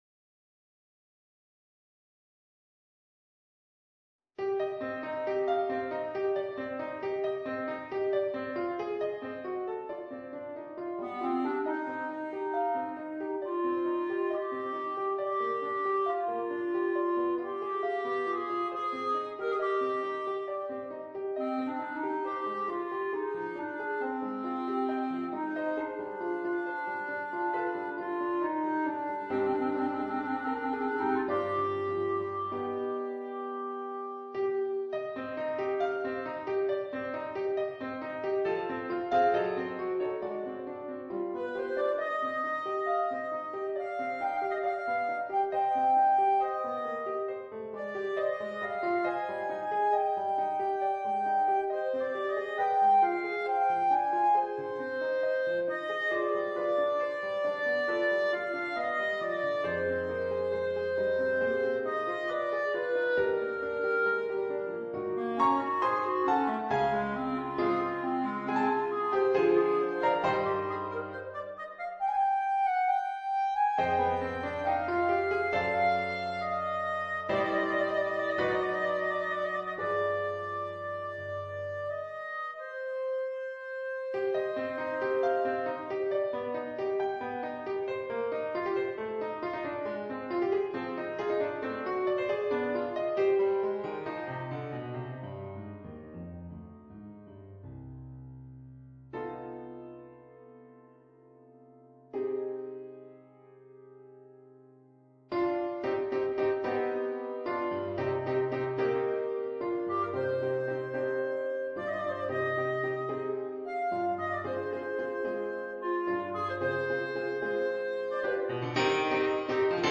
for clarinet and piano